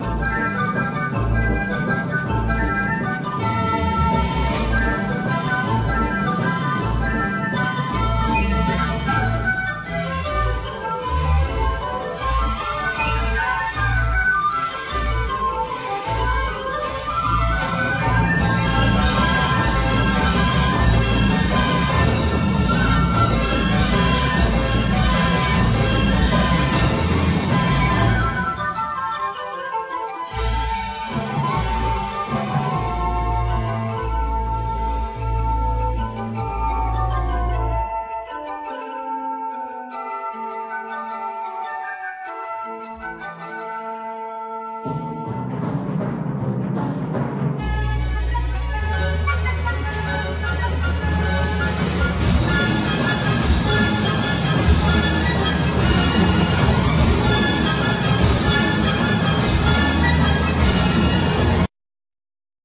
Keyboards,Piano
Bass
Drums
Guitar, Guitar-synth, Synthsizer, Arp Avatar